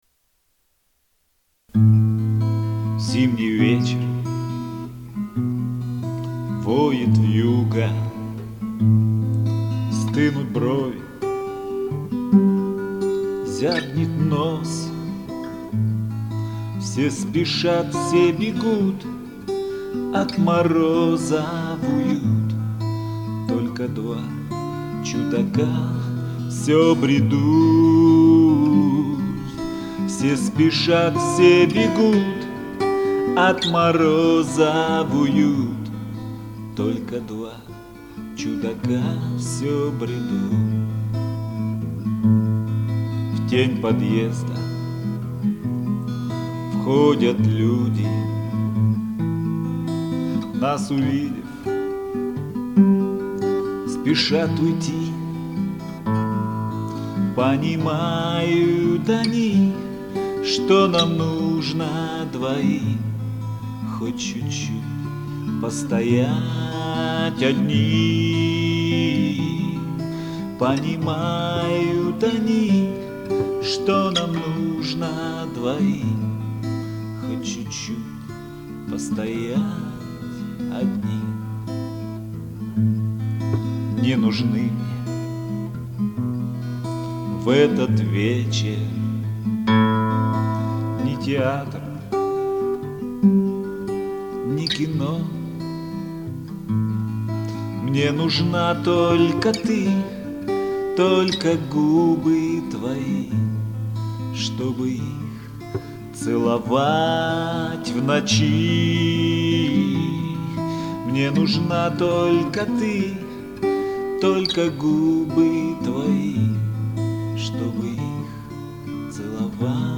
Армейские и дворовые песни под гитару
Хорошо,только гитара тихо.